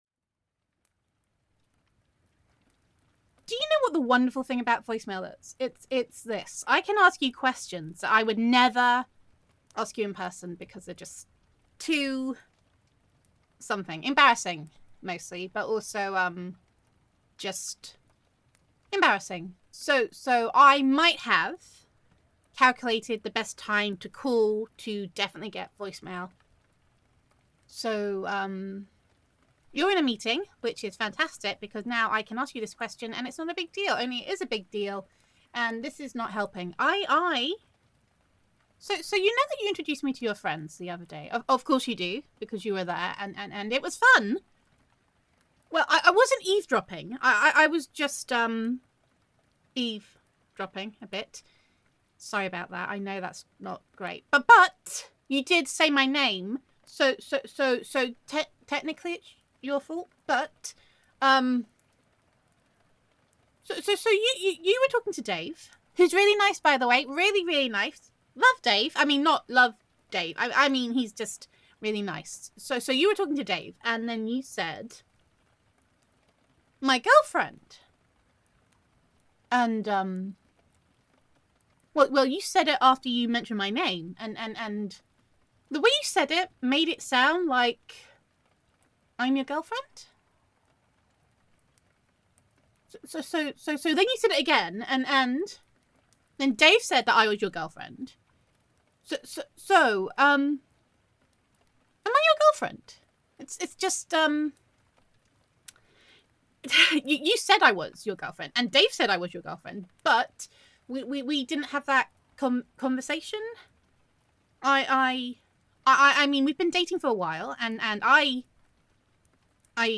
[F4A] I Wasn’t Eavesdropping But… [Girlfriend Roleplay][Girlfriend Voicemail][Voicemail][Sugar High][Overcaffeinated][Adorkable][Gender Neutral][DAVE!][Your Girlfriend Thinks That She Might Be Your Girlfriend, She Has Also Had a Lot of Caffeine and Sugar]